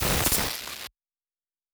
pgs/Assets/Audio/Sci-Fi Sounds/Electric/Glitch 2_10.wav at master
Glitch 2_10.wav